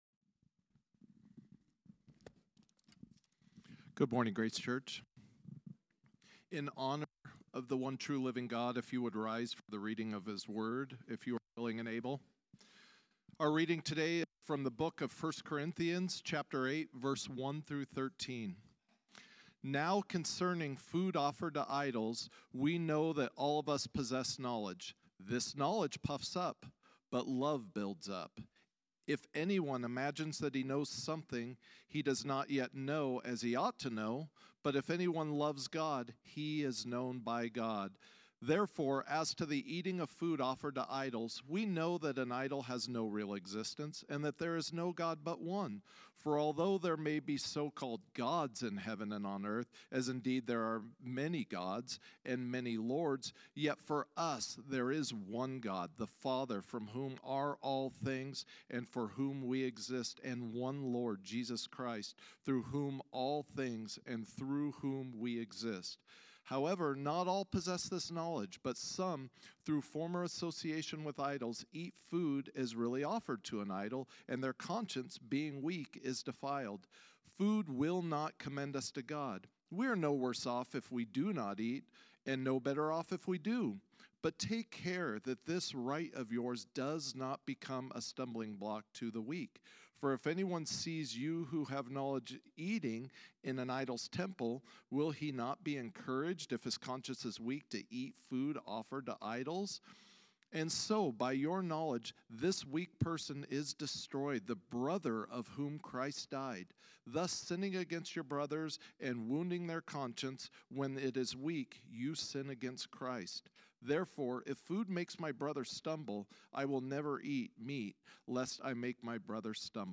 A message from the series "Discipleship Essentials."